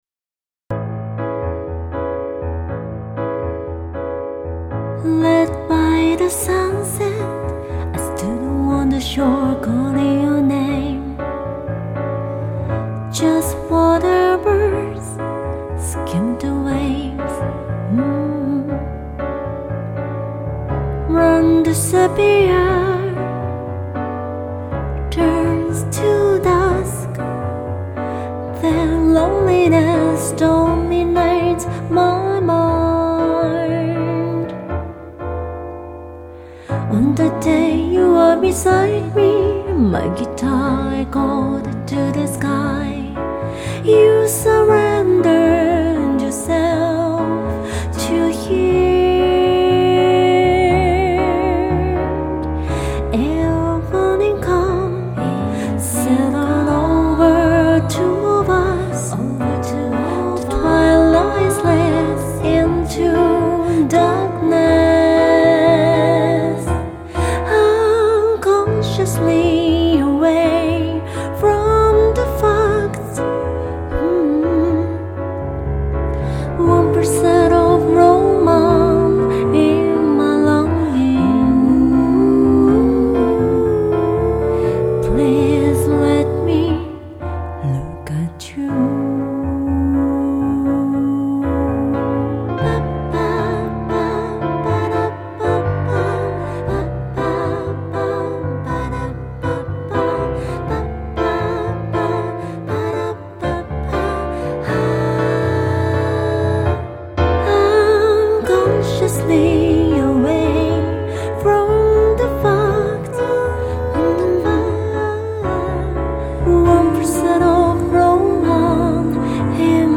静岡県磐田市出身／シンガーソングライター